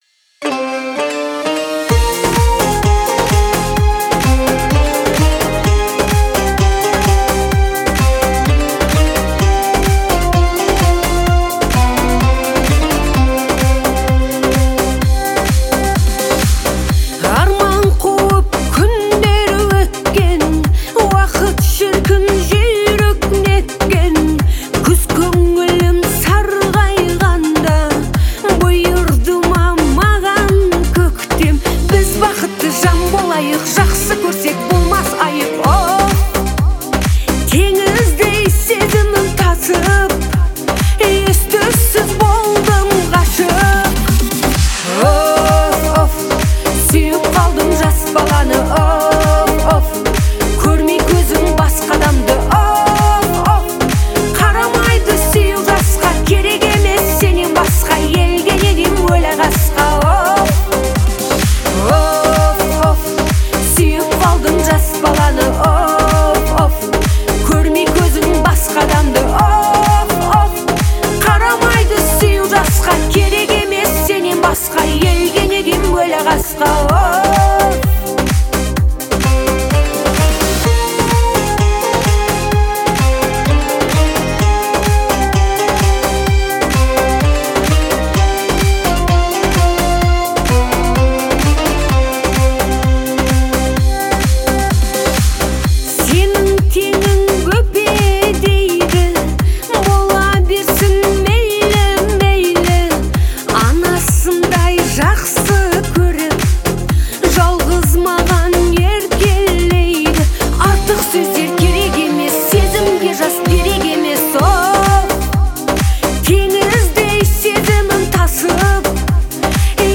выразительный вокал